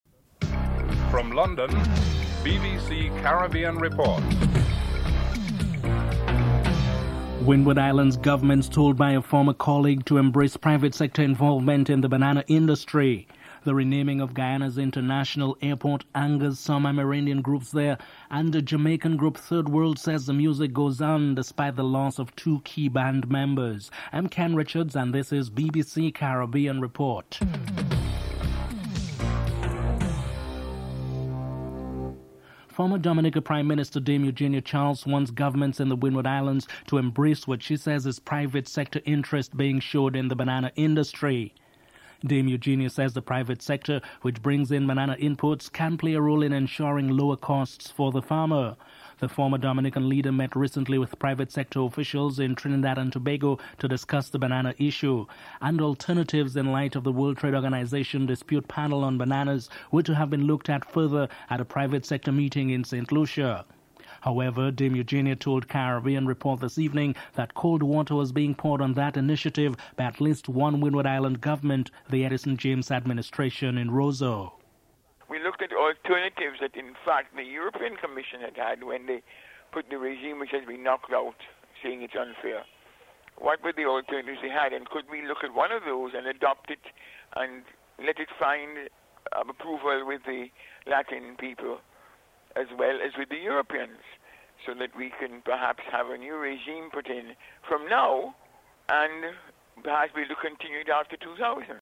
1. Headlines (00:00-00:31)
Dame Eugenia Charles, former Prime Minister of Dominica is interviewed (00:32-04:49)